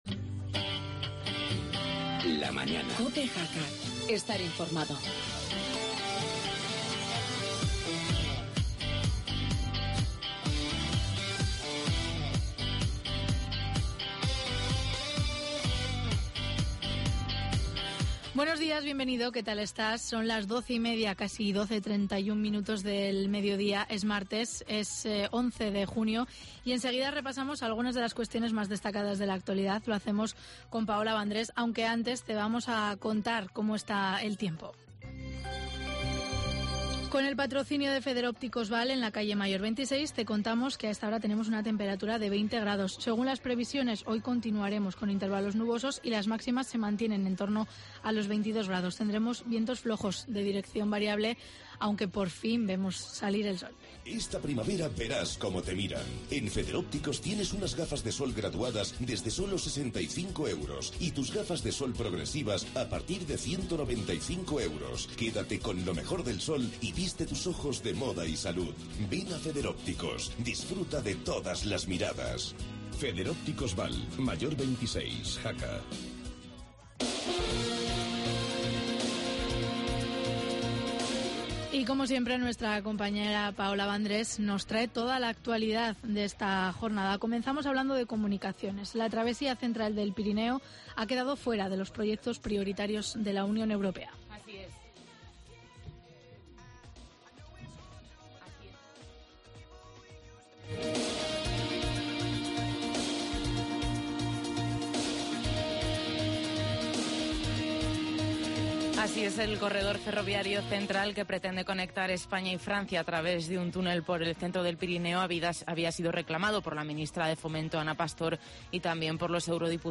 tertulia municipal